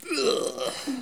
Les sons ont été découpés en morceaux exploitables. 2017-04-10 17:58:57 +02:00 176 KiB Raw History
beurk_03.wav